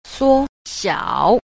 2. 縮小 – suōxiǎo – súc tiểu (thu nhỏ)